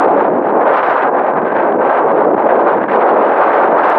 wind_ext.wav